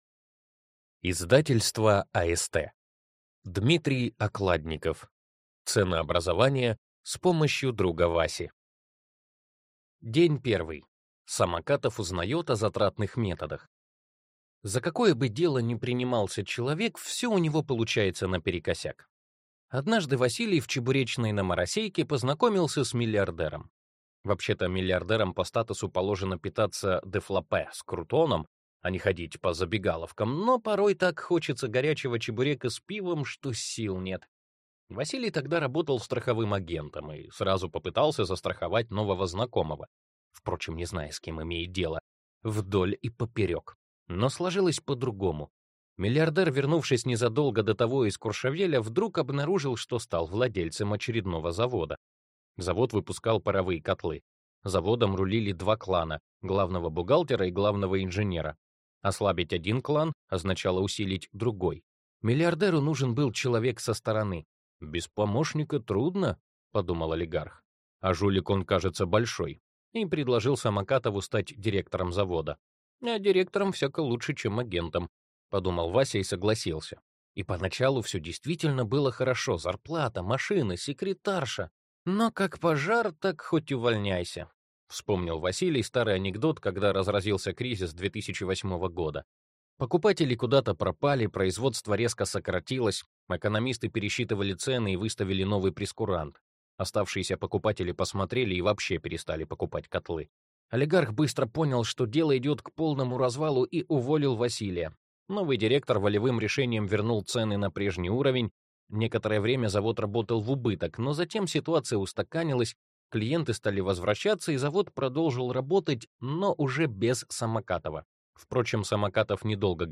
Аудиокнига Ценообразование с помощью друга Васи | Библиотека аудиокниг